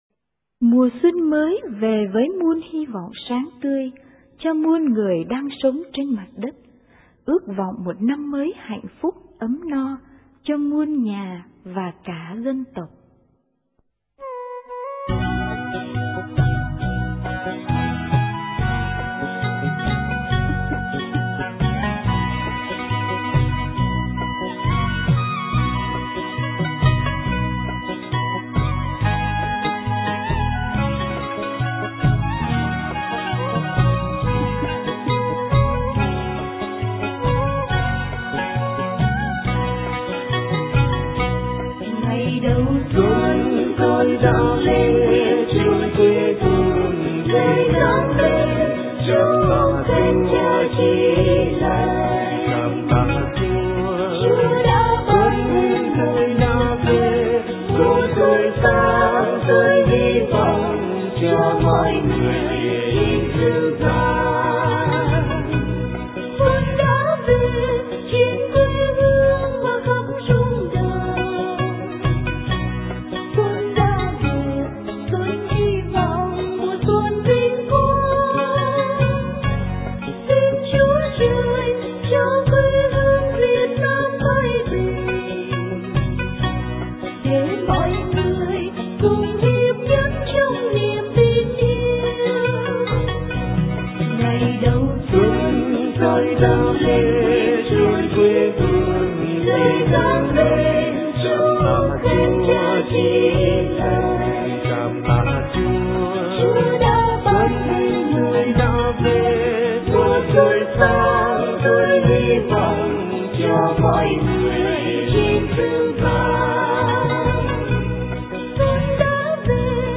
* Thể loại: Mừng Xuân